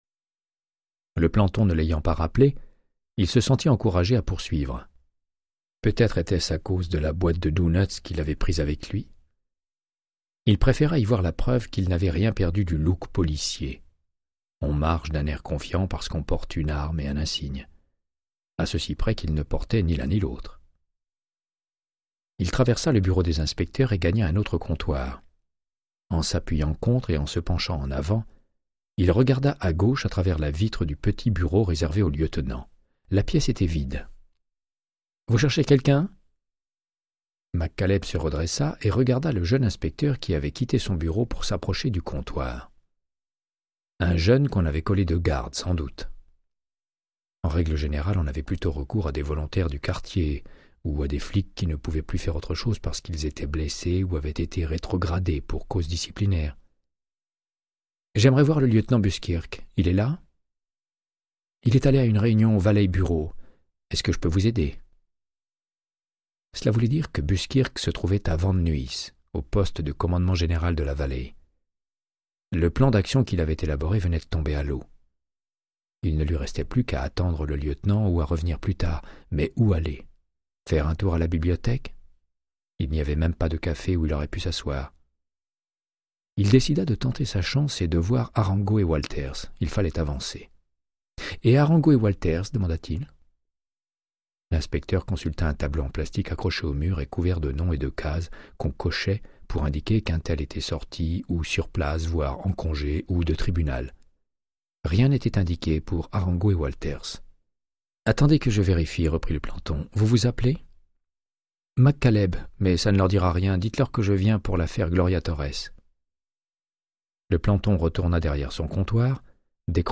Audiobook = Créance de sang, de Michael Connellly - 13